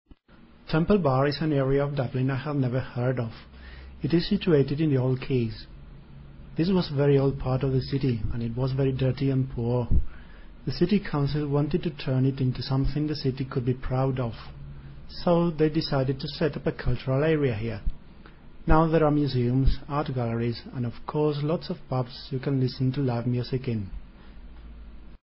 Lenguaje hablado